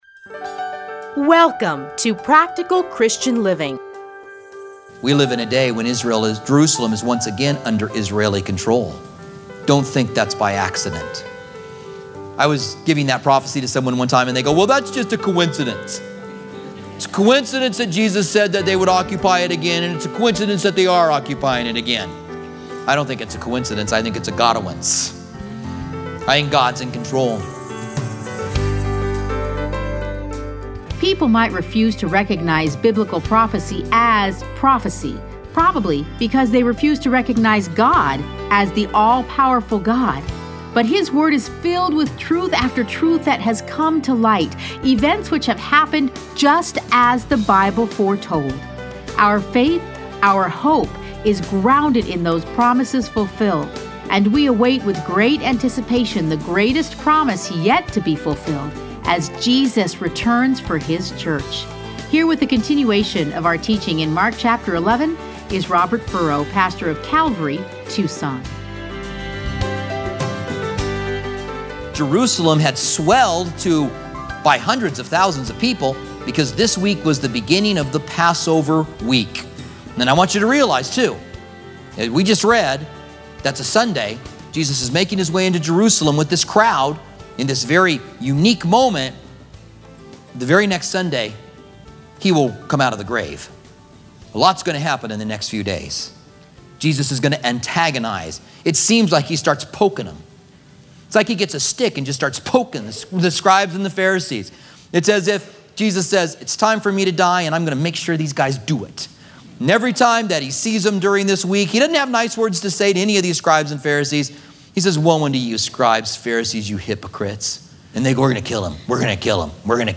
Listen to a teaching from Mark 11.